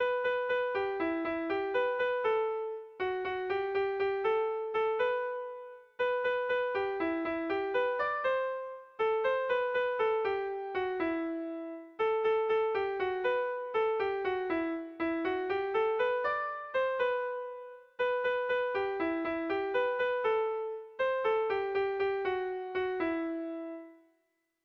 Kontakizunezkoa
Zortziko handia (hg) / Lau puntuko handia (ip)
A1A2BA2